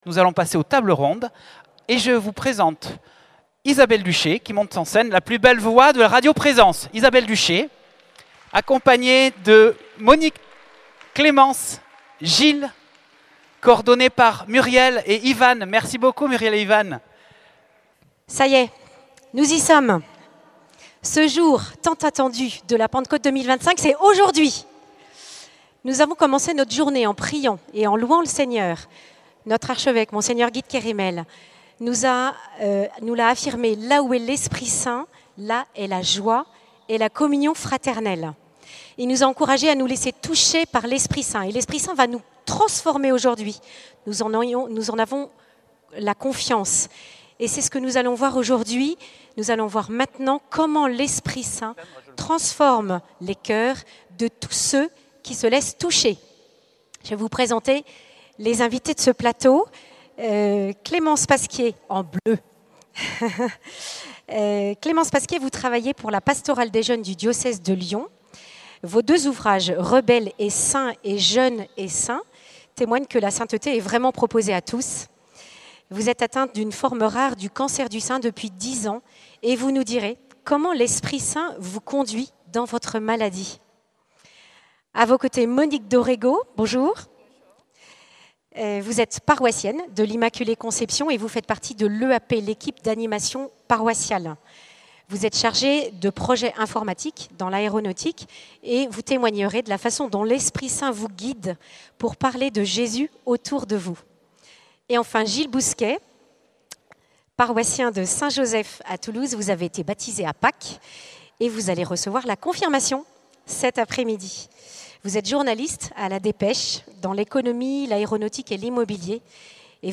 Cette table ronde a eu lieu lors du rassemblement du diocèse de Toulouse, dimanche de Pentecôte.